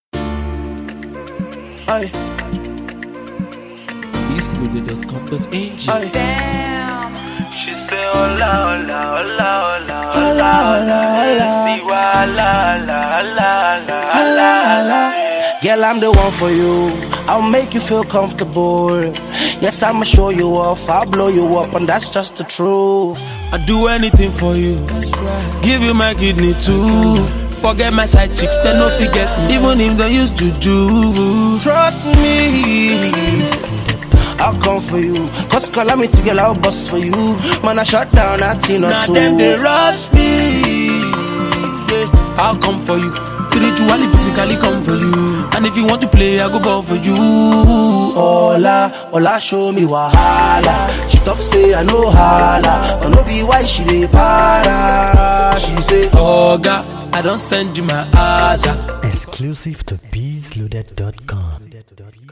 super sensual cut